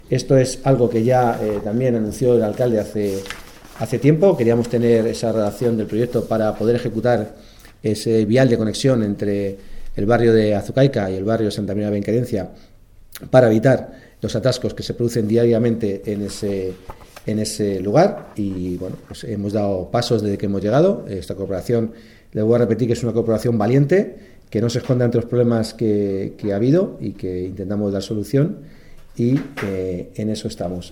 La Junta de Gobierno Local ha adjudicado esta semana la redacción del proyecto del Plan Especial de Infraestructuras del vial de conexión entre los barrios de Azucaica y el Polígono. Así lo ha explicado hoy el portavoz municipal, Juan José Alcalde en la rueda de prensa donde ha dado a conocer los asuntos adoptados en dicha reunión.